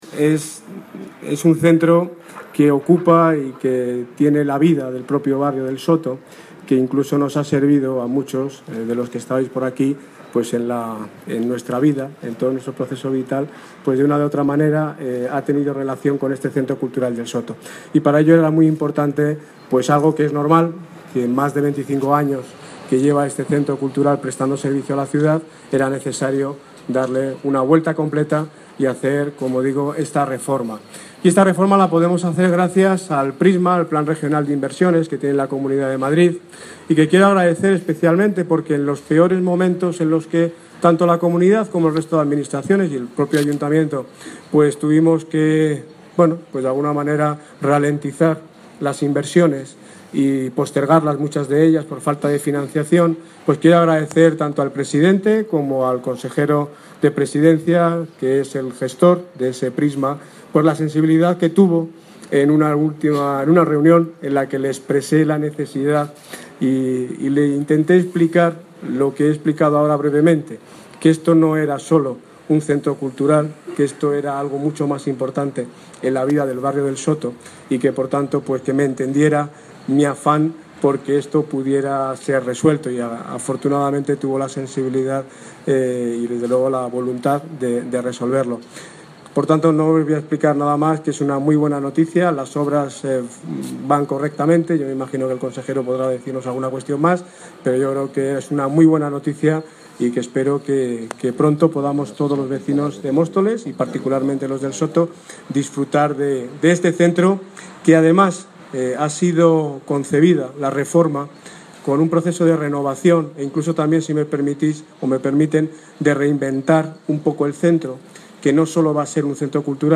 Audio - Daniel Ortiz (Alcalde de Móstoles ) Sobre Remodelación CC El Soto